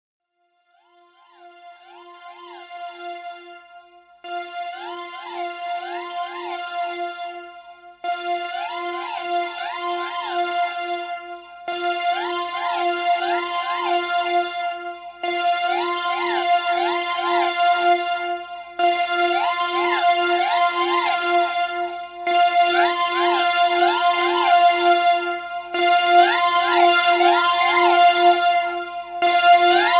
Increases in volume over 30 seconds to maximum